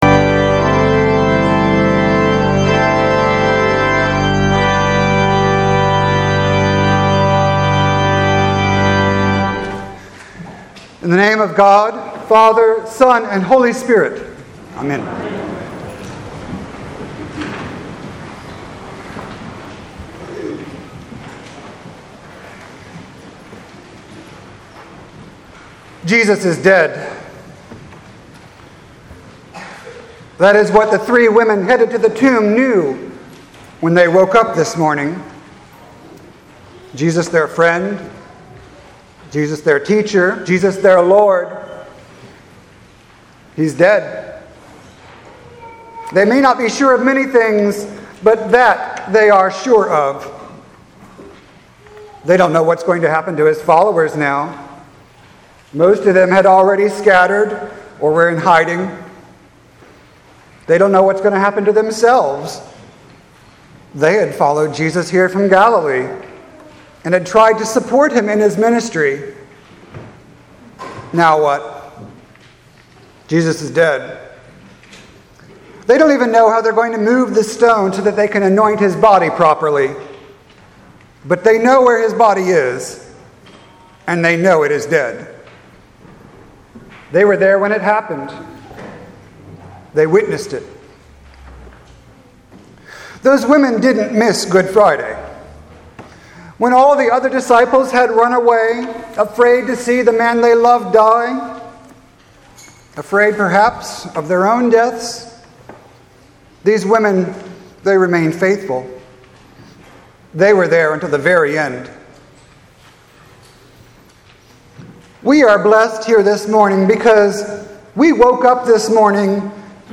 Sermon for Easter Sunday 2018